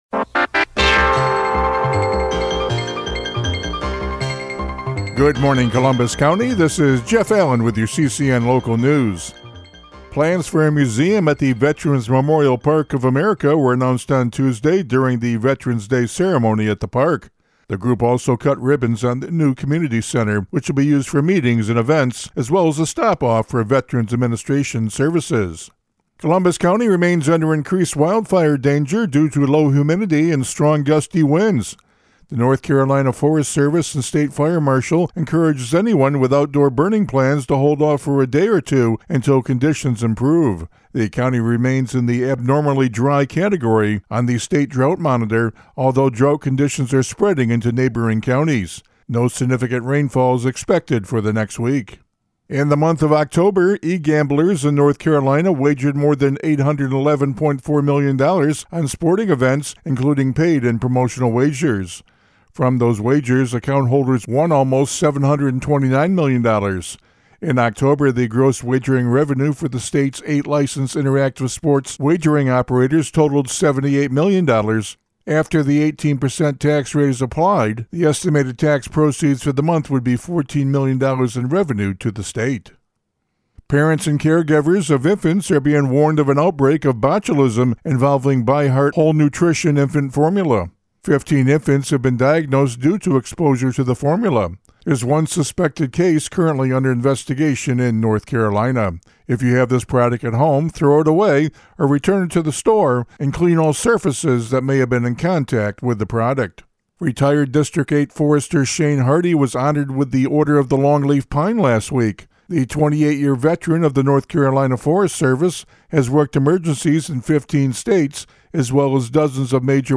CCN Radio News — Morning Report for November 13, 2025